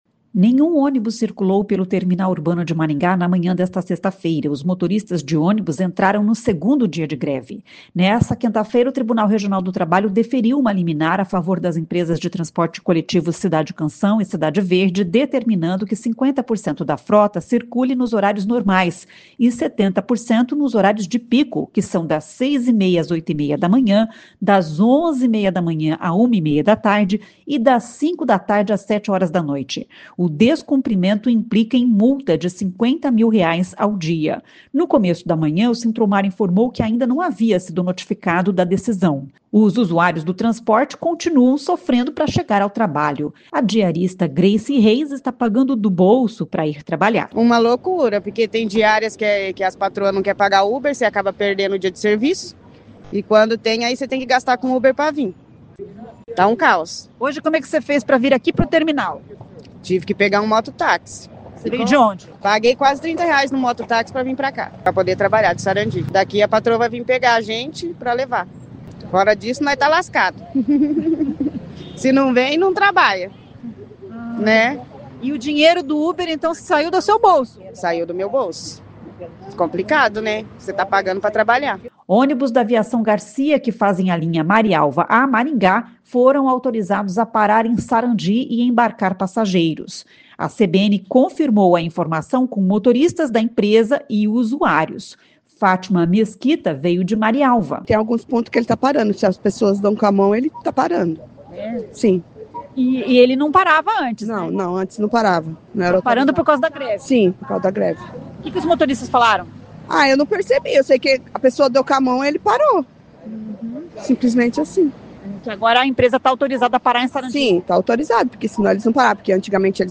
Em coletiva de imprensa, o prefeito se disse surpreso com a intransigência do sindicato em negociar.